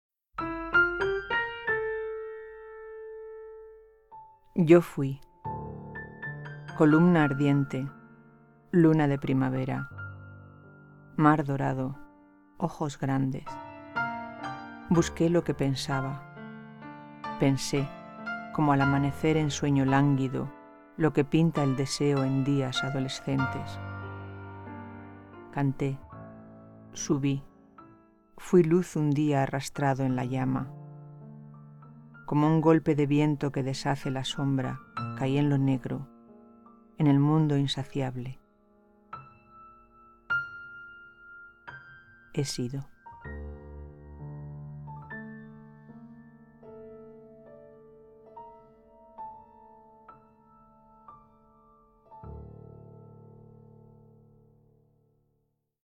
recitar